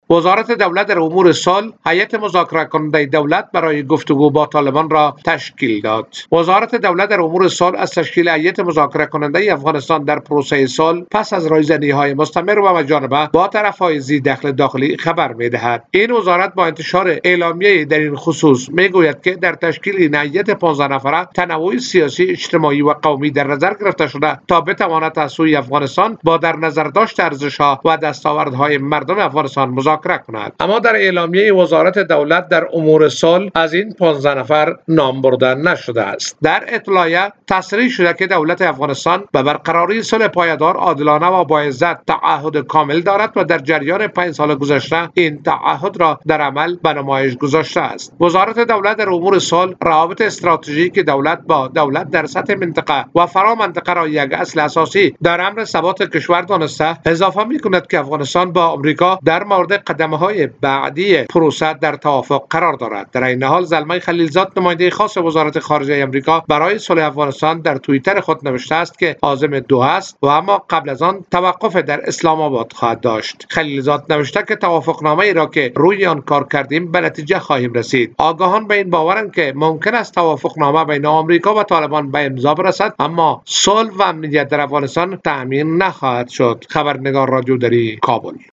جزئیات بیشتر در گزارش خبرنگار رادیودری: